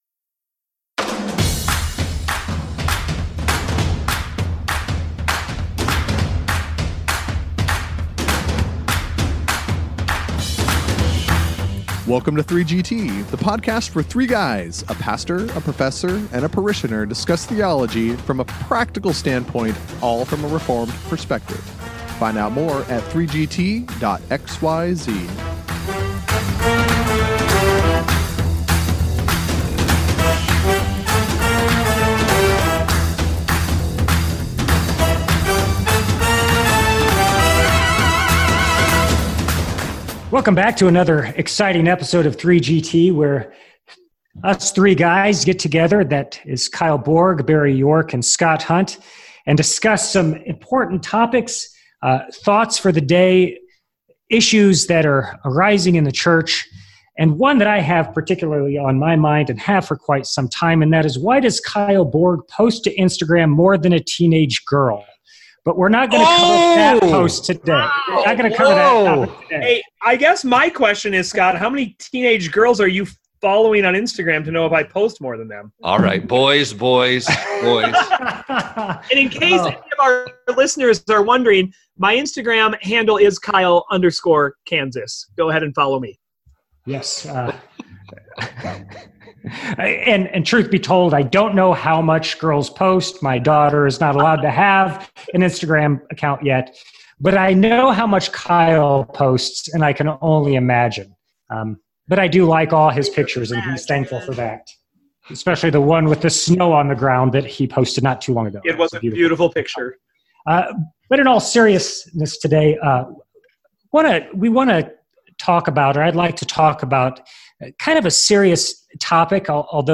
After a rip-roaring introduction, the parishioner leads the pastor and the prof into the topic of the day: pastoral morality or the lack thereof. He begins by highlighting the downfall of many prominent pastors and how many of them have returned to ministry again.
So the guys seriously dig into the discussion. The Biblical emphasis on character for pastors is highlighted.